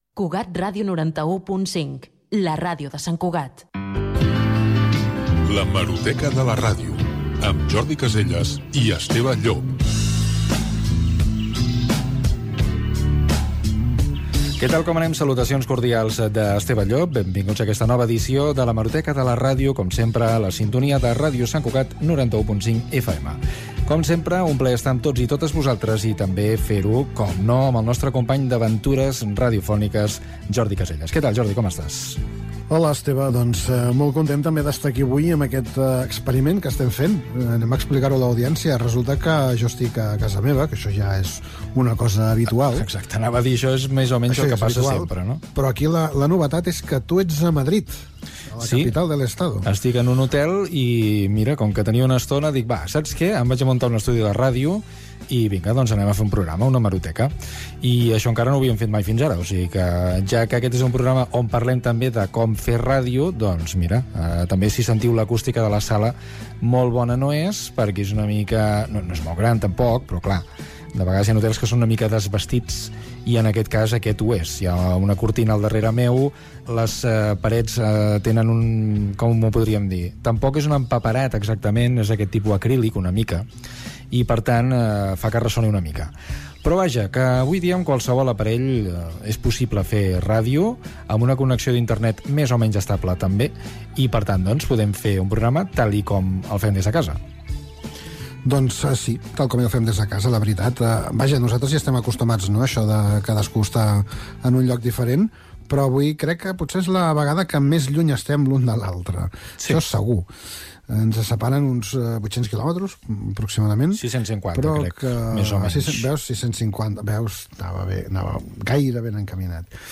Indicatiu de l'emissora
Gènere radiofònic Divulgació